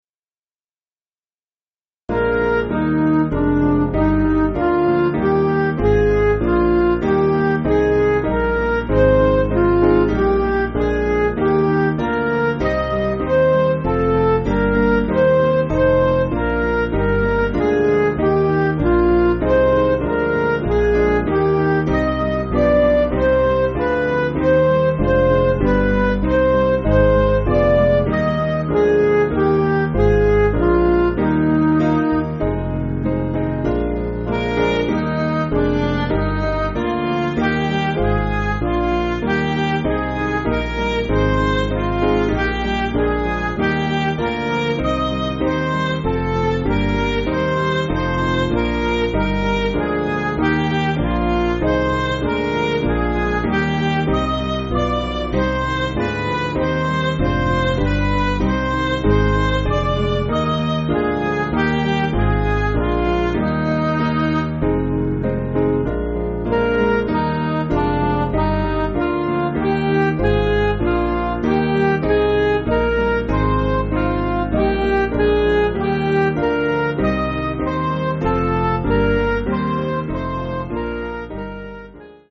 Piano & Instrumental
(CM)   4/Eb
Midi